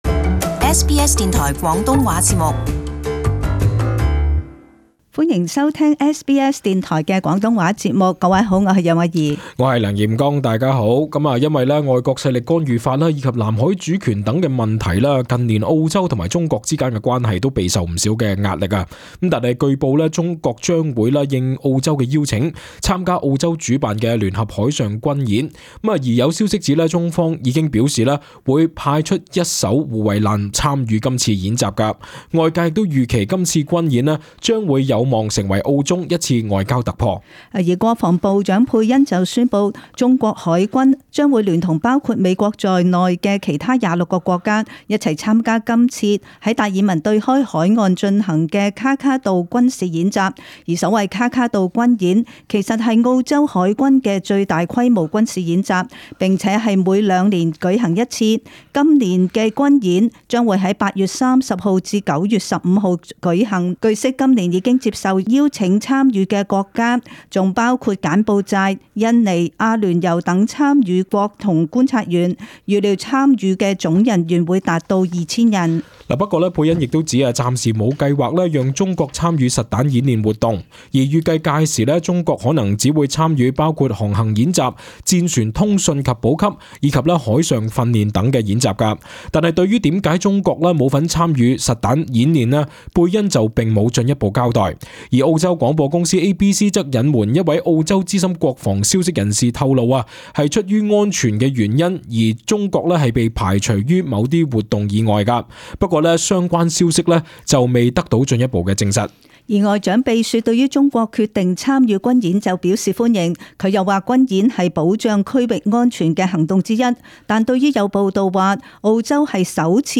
【時事報導】中國應邀來澳參與聯合演習